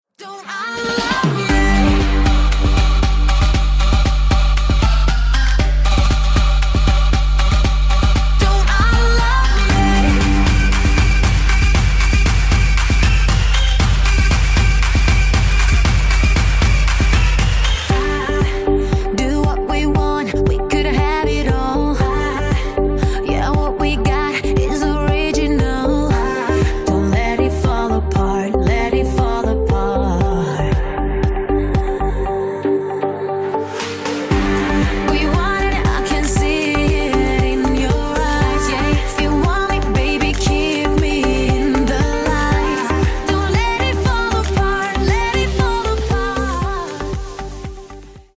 • Качество: 160, Stereo
поп
женский вокал
dance
Trap